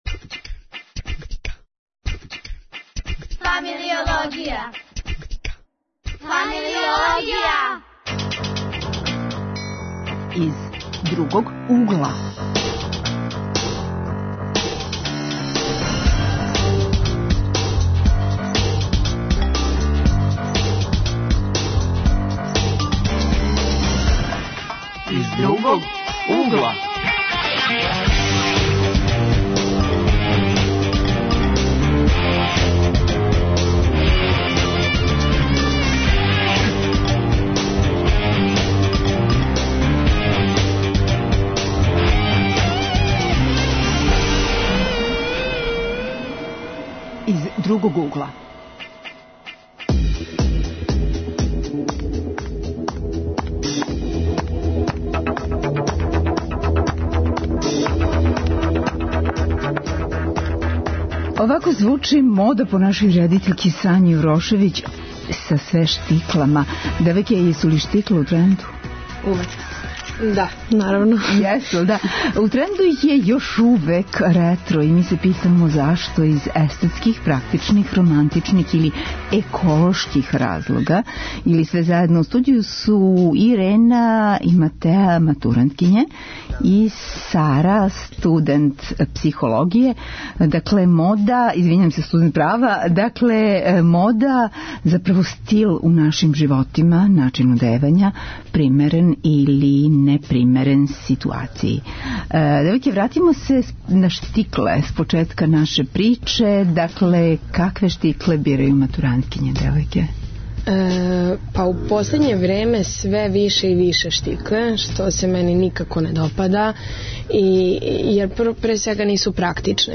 Гости - средњошколци и студенти. Редовне рубрике: АШ - историјска занимљива - опасна мода; Глас савести; Зона културе.